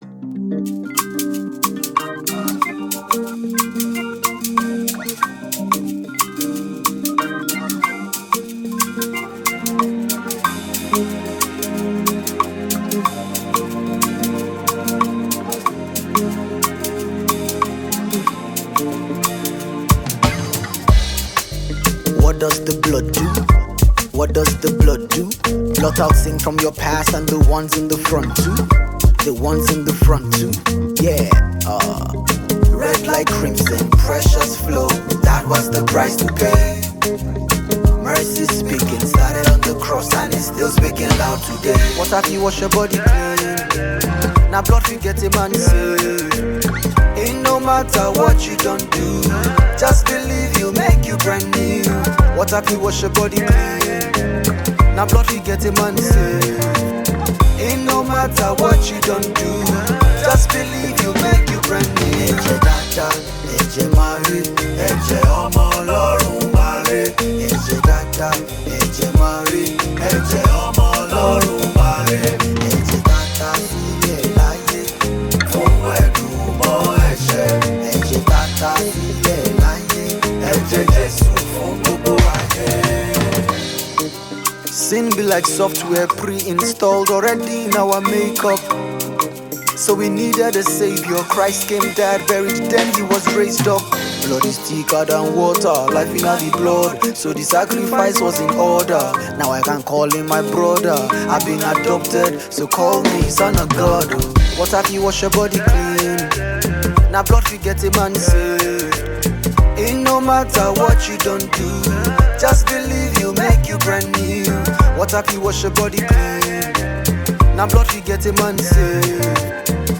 March 31, 2025 Publisher 01 Gospel 0